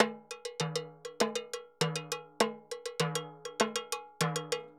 Timbaleta_Salsa 100_4.wav